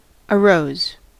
Ääntäminen
Ääntäminen US : IPA : [əˈrəʊz] Tuntematon aksentti: IPA : /əˈrɔʊs/ Haettu sana löytyi näillä lähdekielillä: englanti Arose on sanan arise imperfekti.